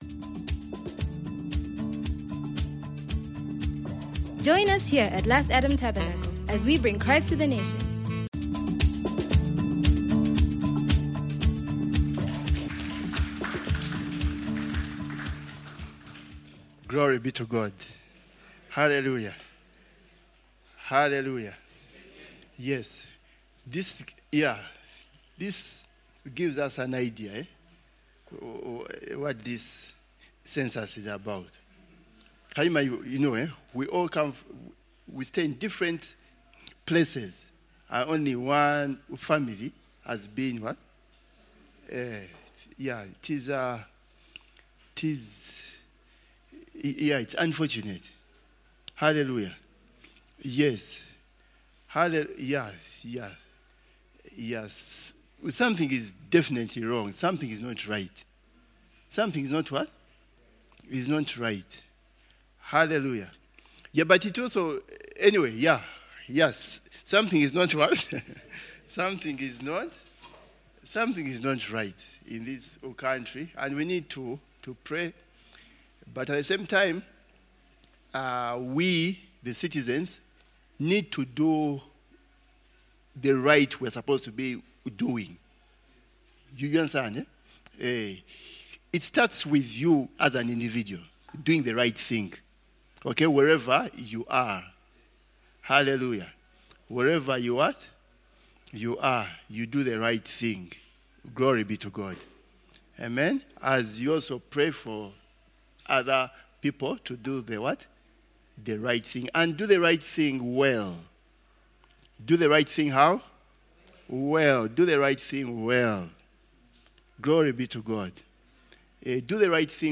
Beyond a shadow of doubt, God recently rewarded us for our service unto Him. In order to be assured of more rewards in this life and that which is to come, we must continue to do a few basic things that are discussed in this sermon.